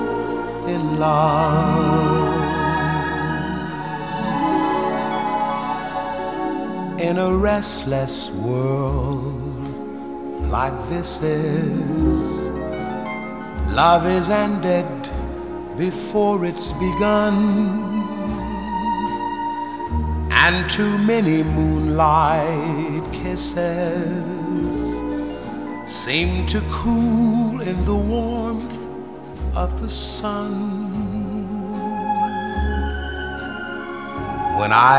Жанр: Поп
# Vocal Pop